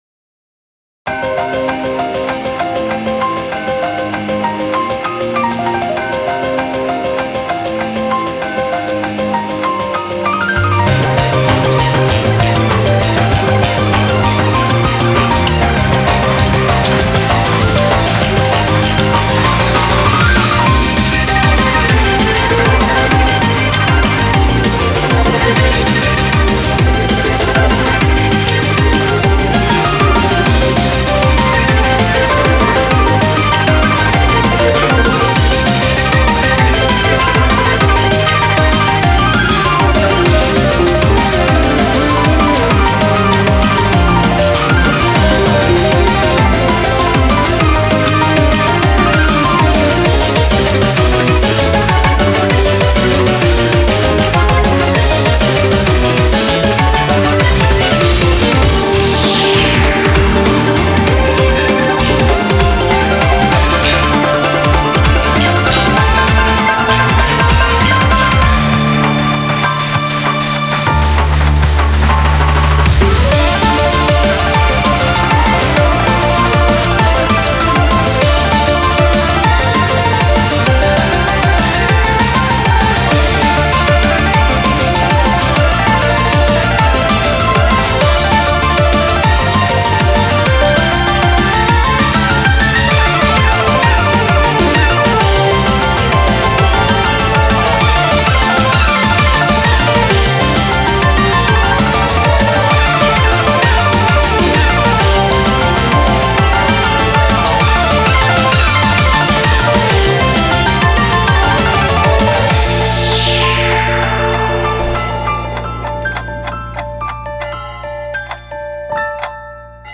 BPM77-196
Audio QualityPerfect (High Quality)
Genre: CHRONO BREAKS.